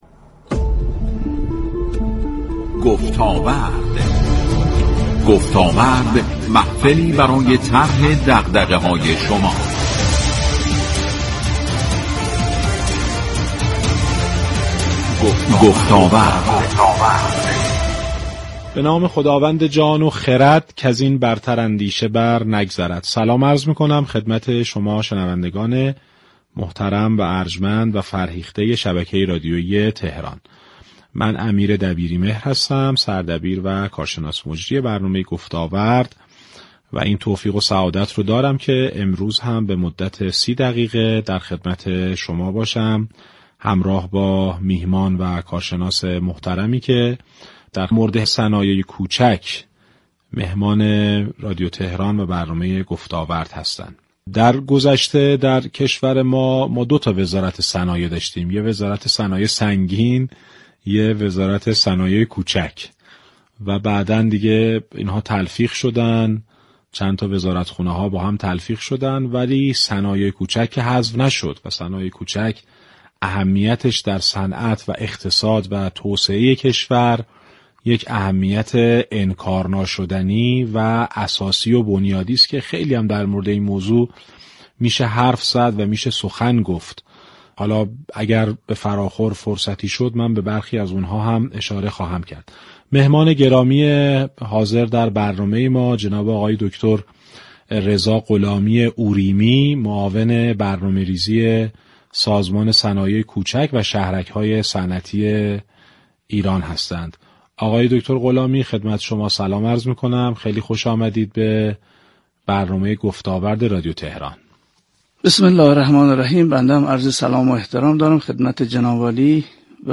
به گزارش پایگاه اطلاع رسانی رادیو تهران، برنامه گفتاورد 5 شهریور با موضوع صنایع كوچك با حضور دكتر رضا غلامی اوریمی، معاون برنامه ریزی سازمان صنایع كوچك و شهرك‌های صنعتی كشور بر روی آنتن رادیو تهران رفت.